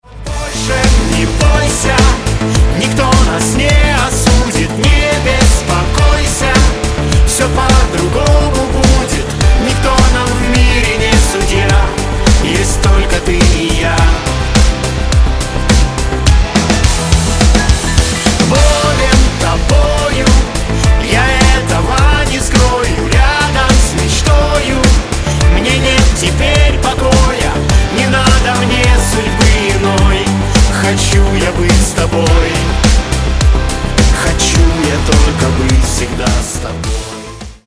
Русские Рингтоны » # Поп Рингтоны